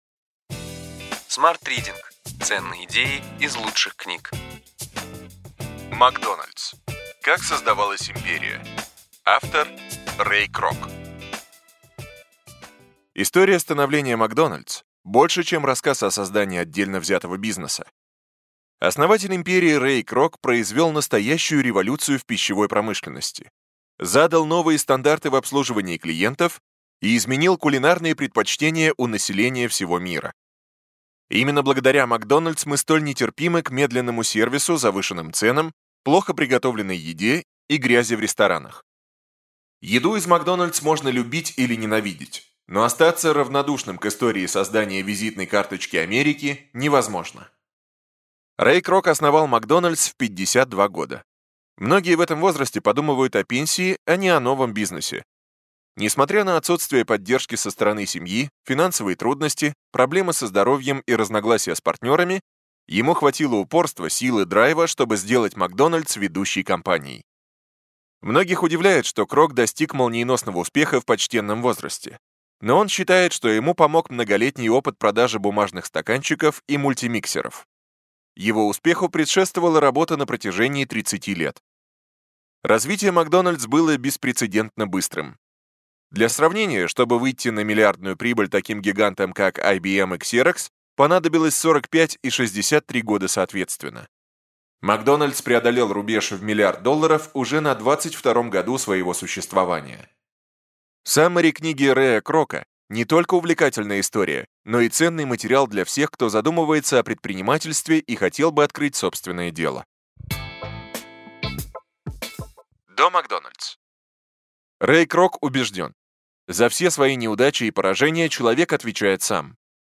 Аудиокнига Ключевые идеи книги: McDonald`s. Как создавалась империя. Рэй Крок | Библиотека аудиокниг